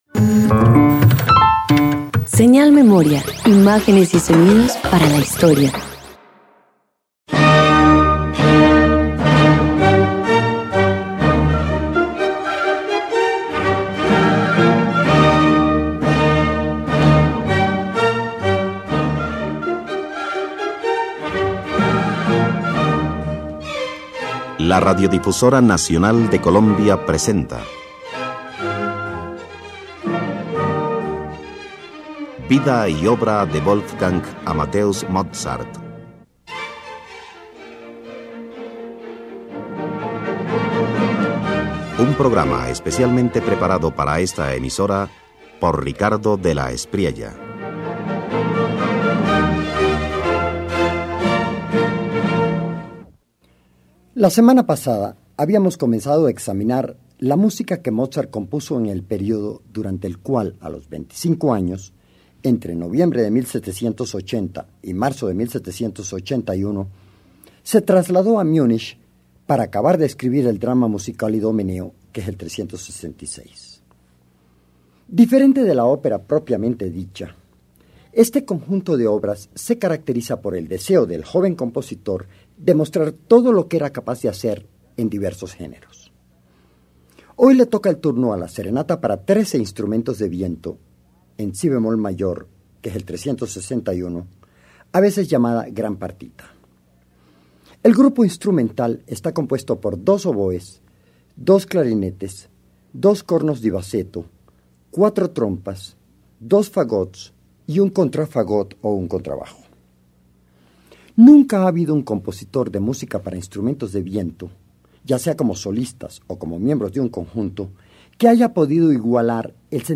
En su prolífica estancia en Múnich, Mozart compone la Gran Partita, una monumental serenata para trece vientos. La obra despliega riqueza tímbrica, formas variadas y momentos sublimes que consolidan su genio en la música para viento.